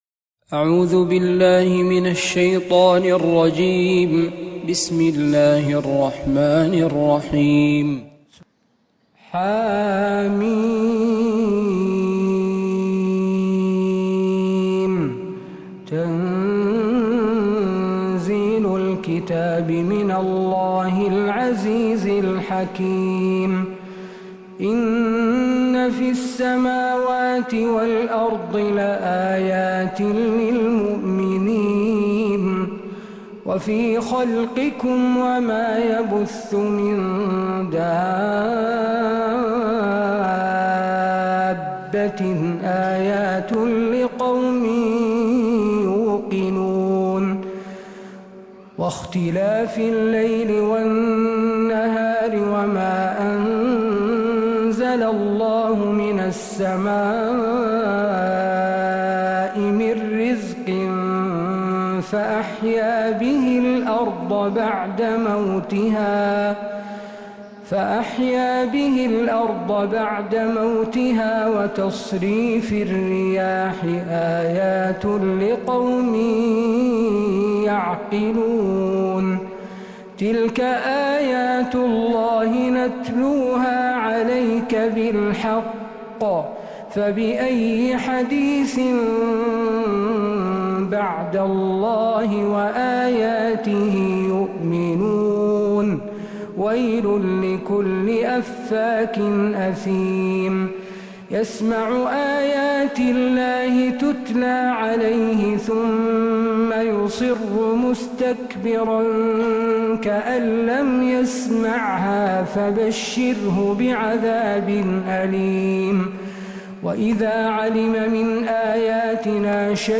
من فجريات الحرم النبوي